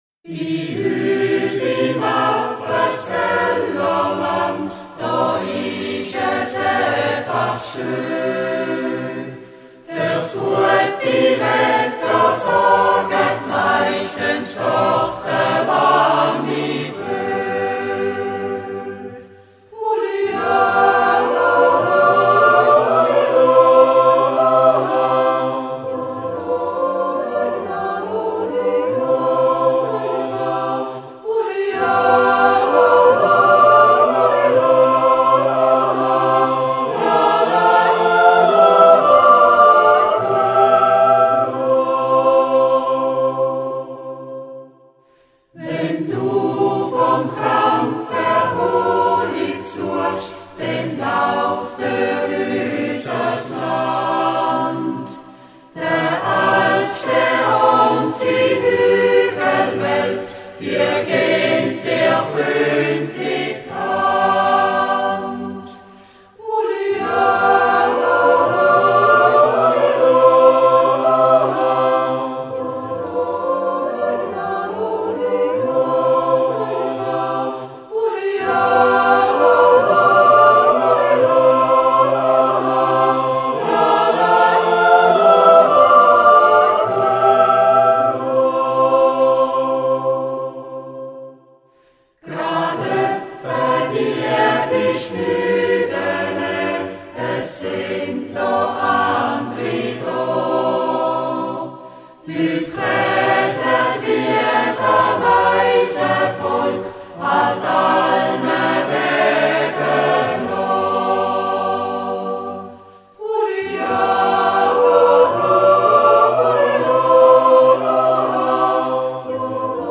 The choir performs traditional songs from the Appenzell region and 'Zäuerli' (natural yodel without words).
CD CH-Records. Trachtenchor Heiden, Muulörgeli- und Jodlergruppe Häädlergruess, Kapelle 'rond ond schlääzig', Alphorntrio Kellenberger, Trio 'näbis anders'.